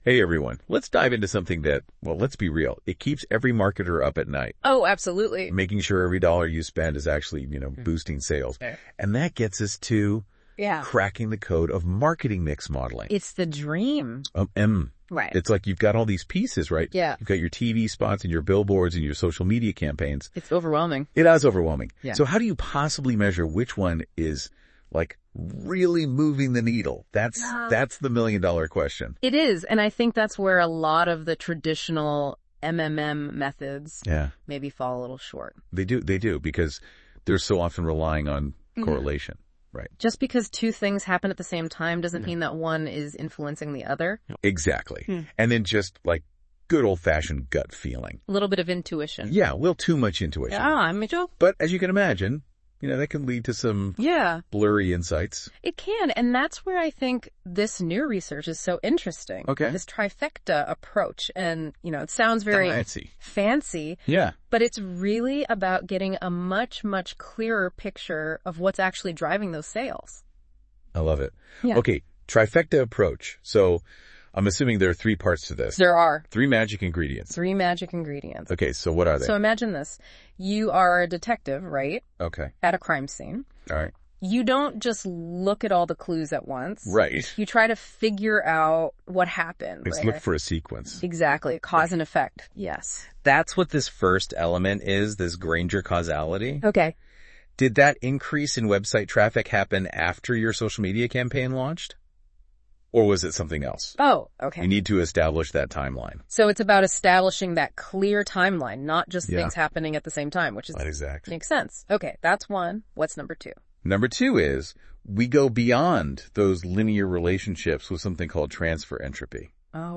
Note : This Podcast is generated through Notebook LM.